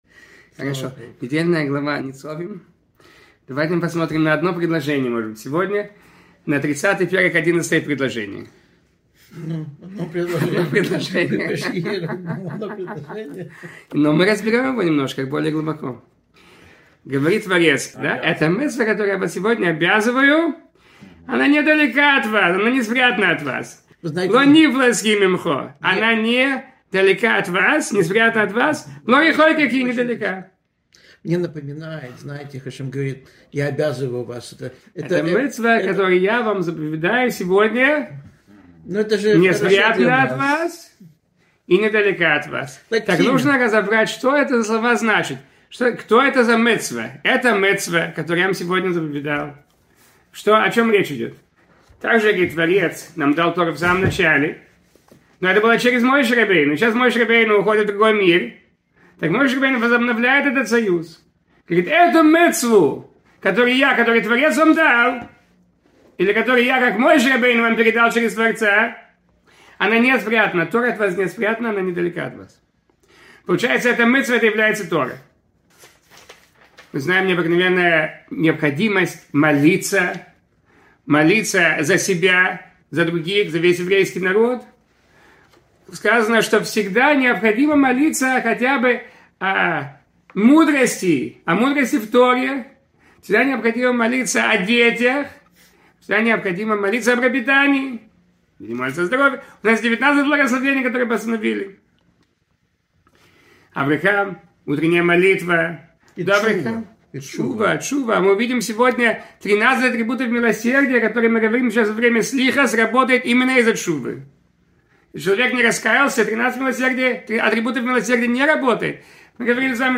Урок с отсылками на некоторые строки книги Дварим (глава Ницавим)